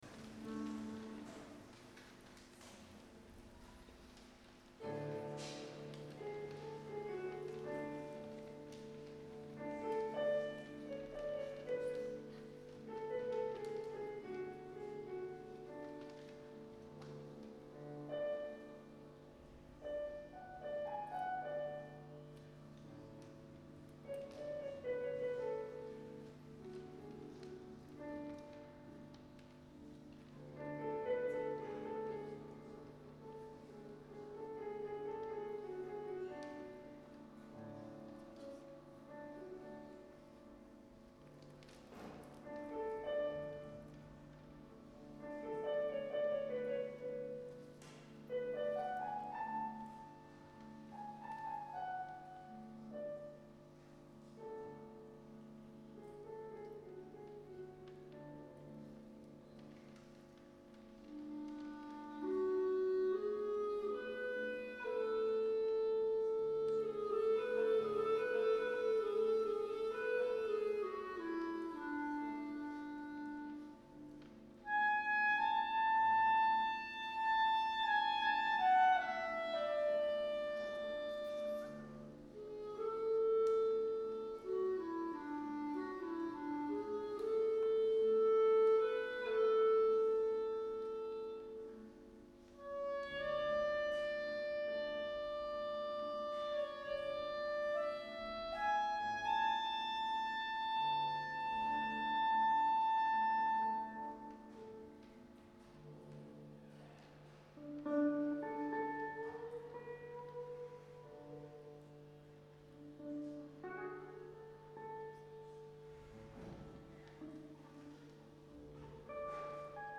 Nahrávka z Adventního koncertu AG
Sbor